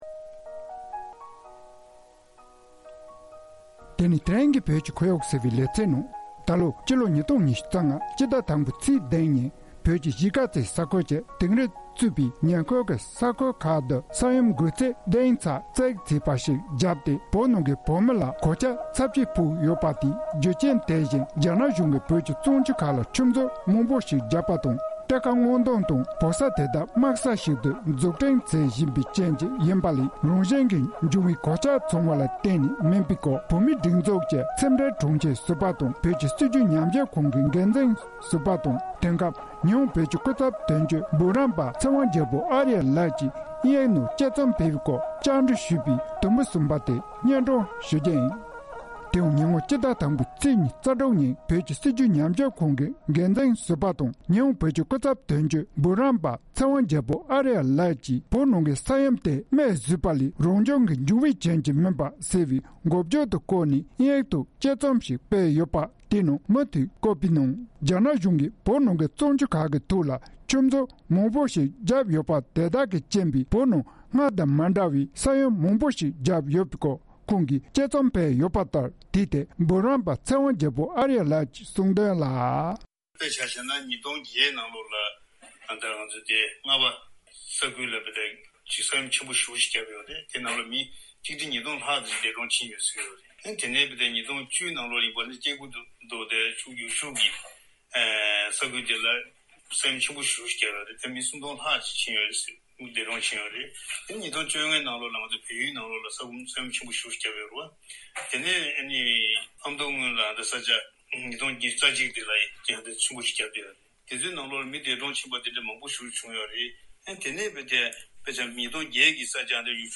བཅའ་འདྲི་ཞུས་པའི་དུམ་བུ་གསུམ་པ་དེ་སྙན་སྒྲོན་ཞུས་པར་གསན་རོགས།།